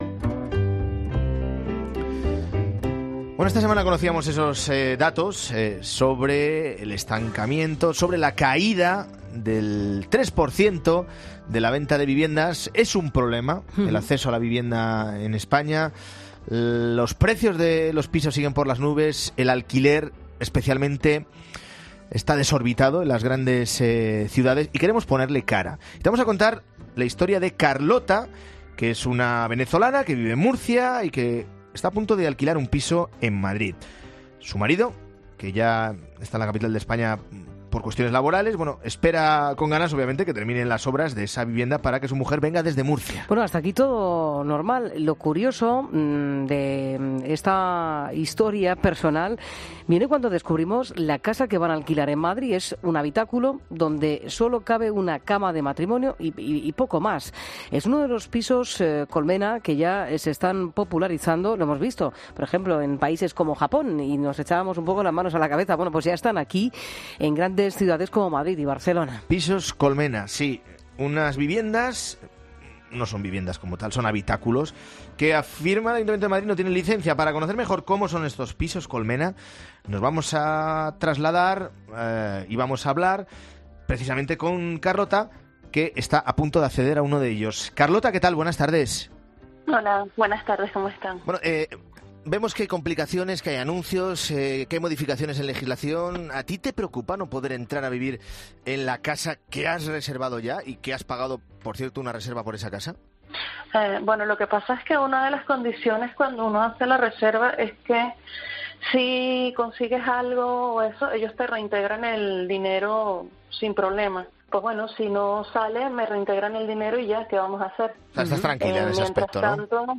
En Mediodía COPE hablamos con la propietaria de uno de estos pisos